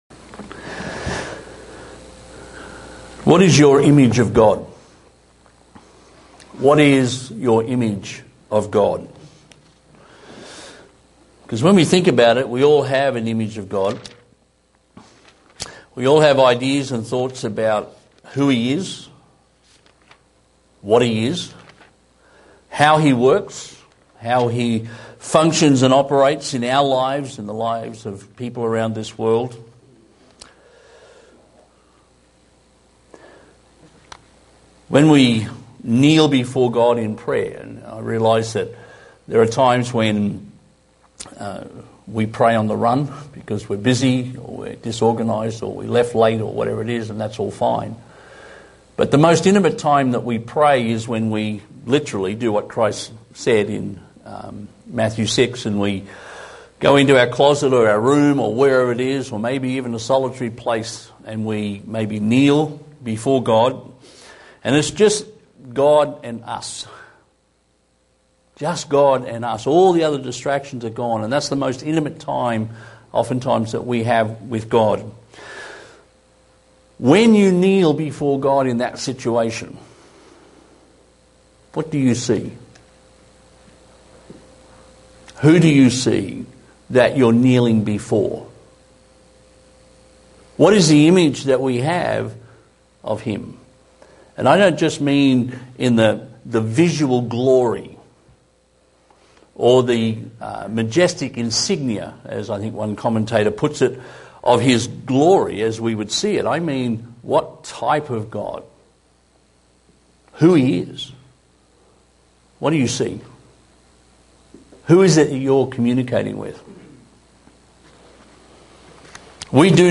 Given in Buffalo, NY Elmira, NY
Print Your image of God will effect how you look at and treat others. sermons Studying the bible?